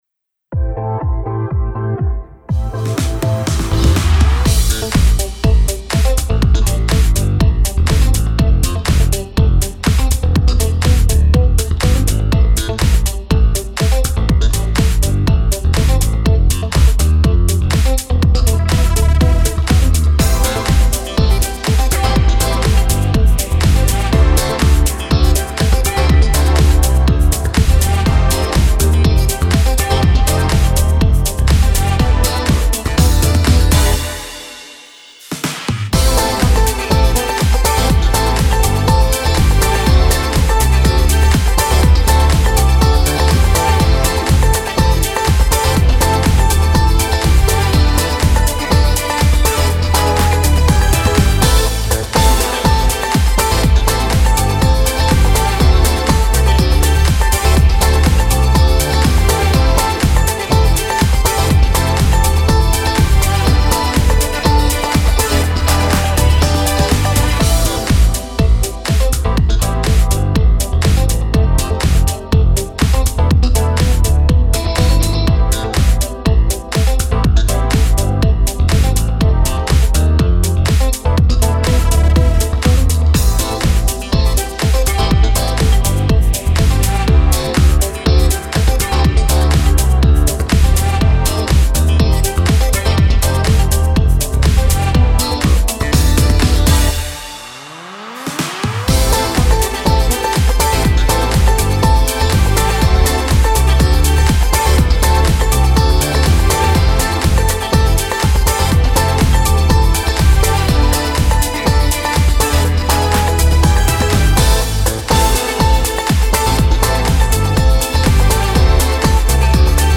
Характер песни: позитивный.
Темп песни: быстрый.
• Минусовка